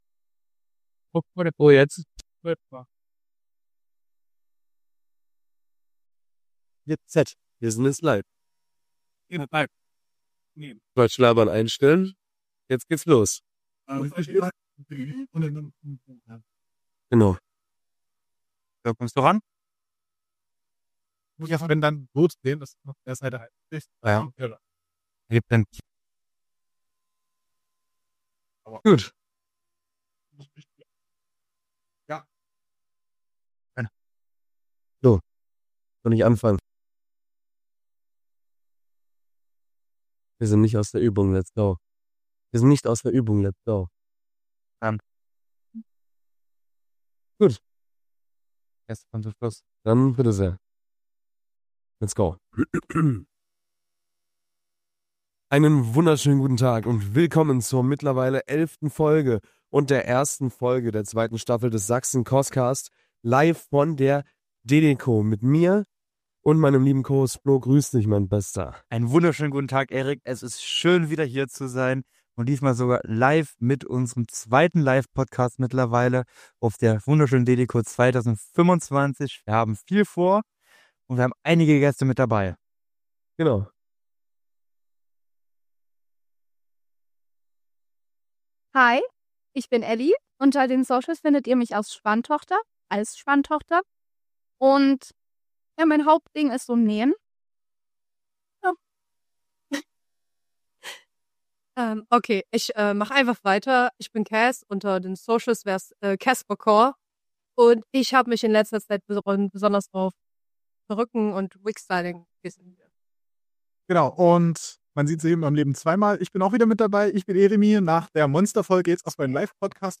Jeder von den Teilnehmern gab seine sicht auf das Cosplayen und die Scene selber und bot damit einen persönlichen Einblick in die ihre Welt des Cosplays. Diese Folge ist dementsprechend völlig ungeschnitten und dadurch kann es zwischendurch kurze ruhige Passagen geben.